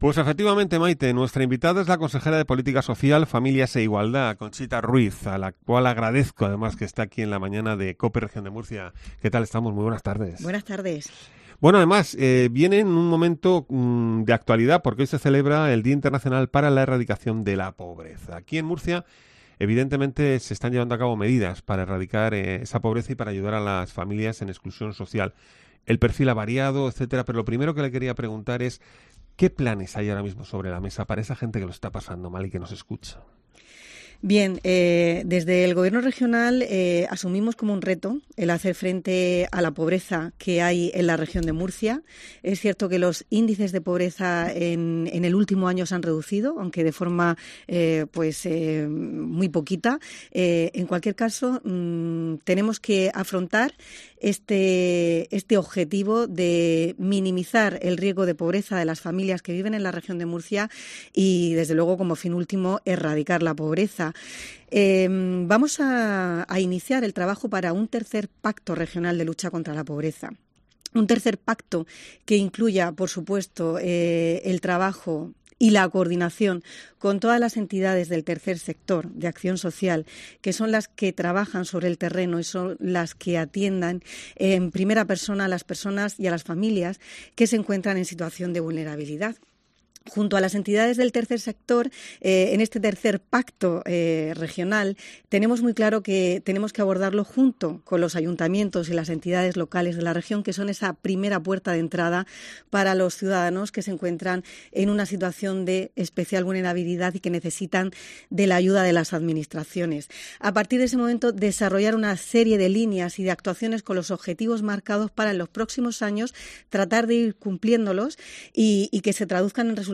En breve vera la luz el tercer pacto regional contra la pobreza. Así lo ha asegurado en los micrófonos de Cope Murcia la Consejera de Política Social, Familias e Igualdad, Conchita Ruiz.